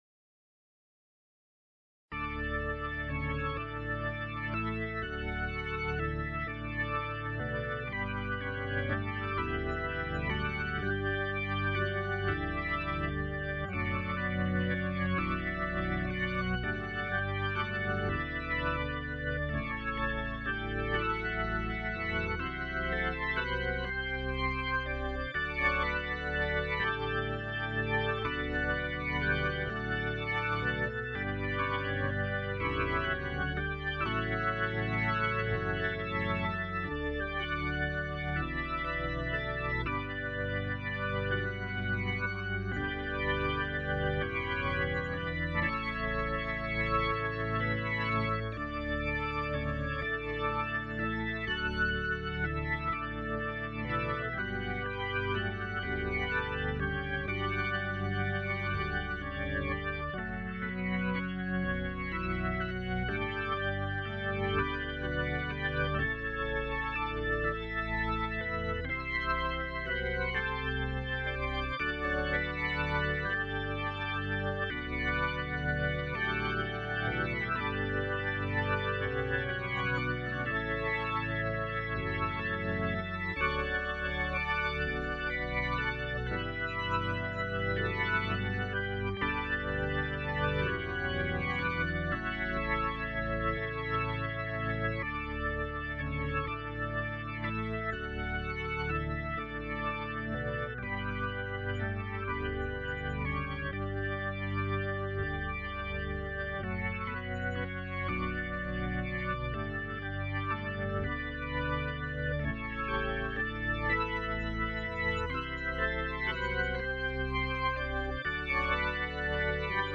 organ.mp3